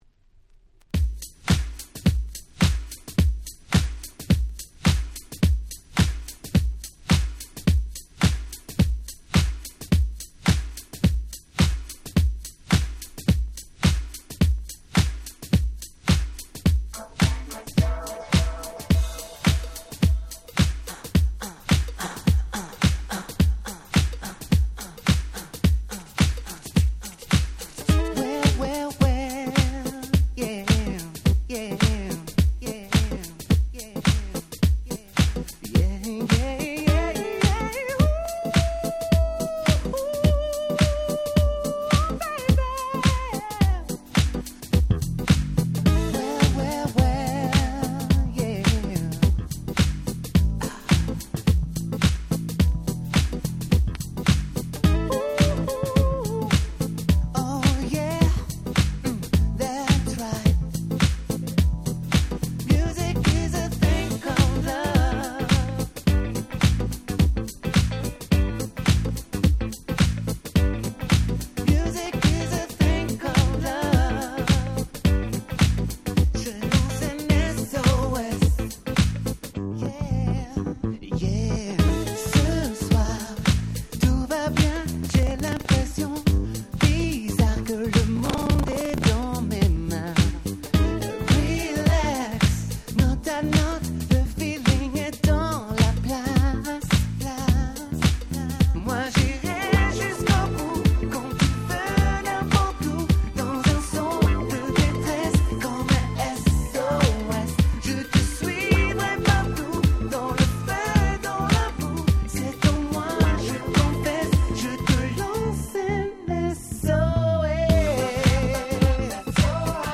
98' Nice French R&B !!
「これぞまさにEU R&B !!」的Groovyでキャッチーな至極の1枚！！